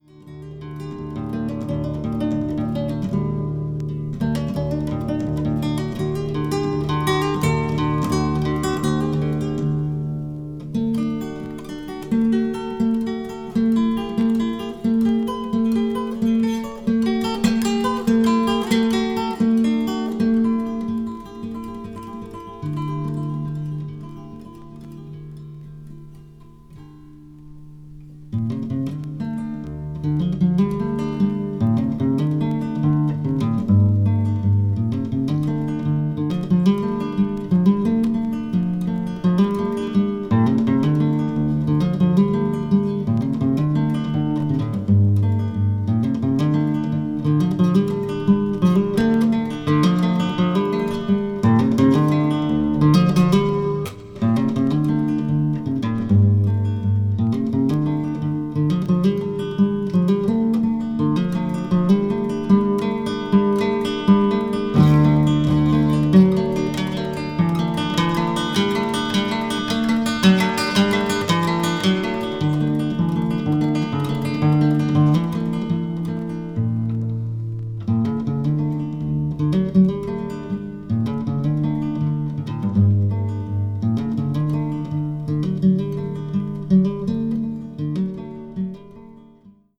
acoustic   country   folk   guitar solo   new age